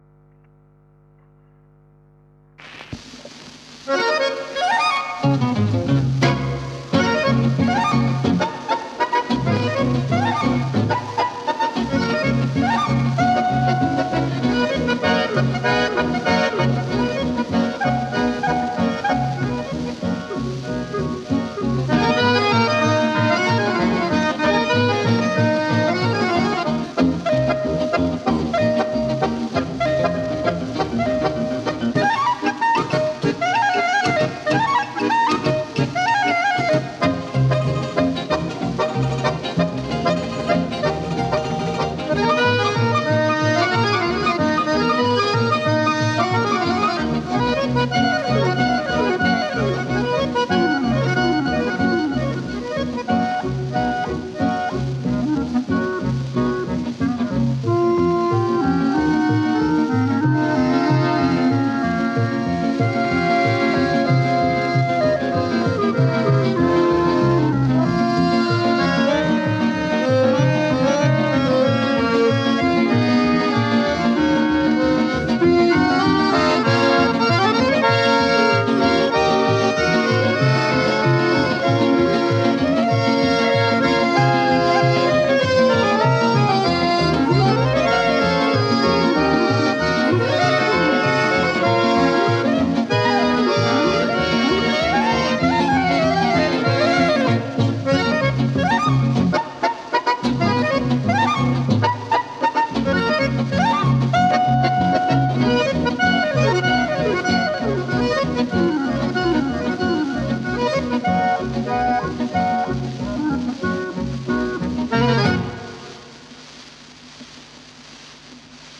аккордеон
гитара
кларнет
к-бас